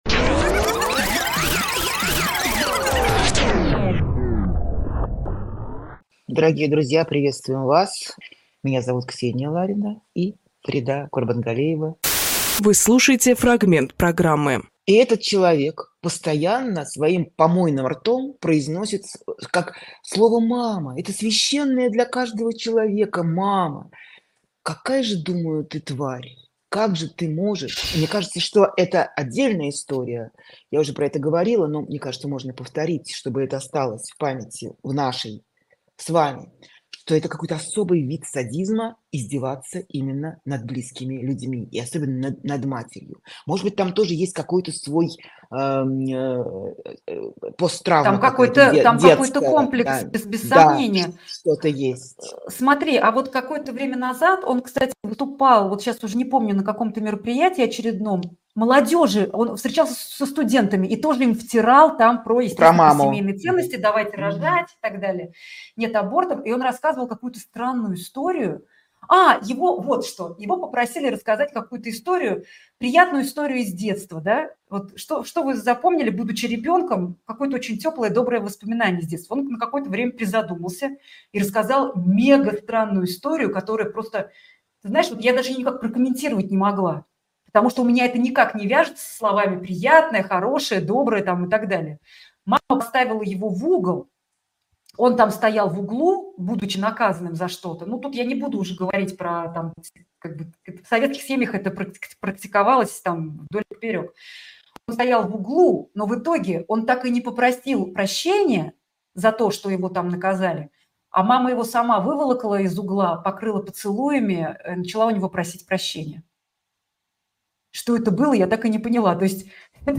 Фрагмент эфира от 2 марта.